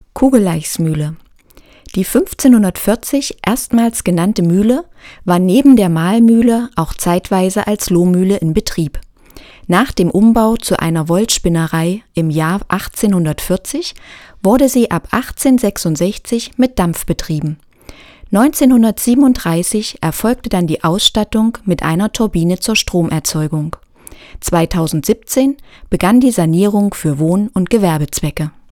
schema:keywords hörfassung